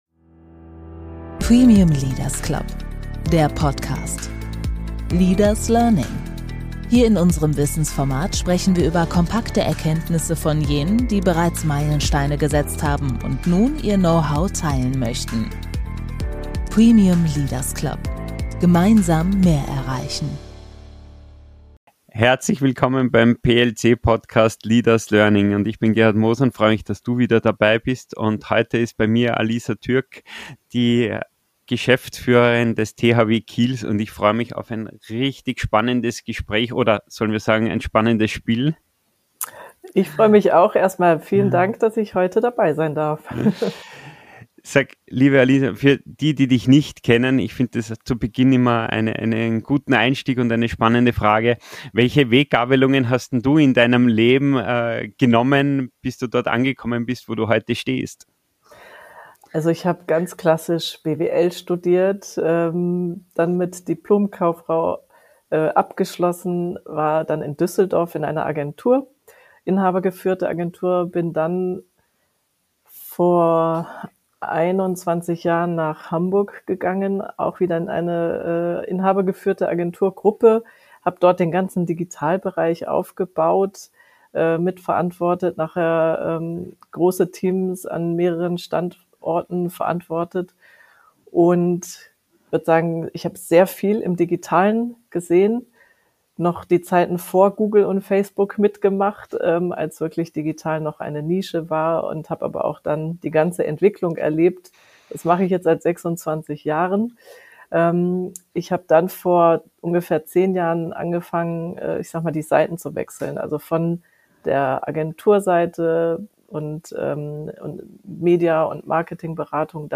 Ein Gespräch über Leadership im Wandel, über Mut zur Veränderung, die Bedeutung von Netzwerken und die Kraft des Sports als gesellschaftlicher Stabilitätsanker – nahbar, inspirierend und praxisnah.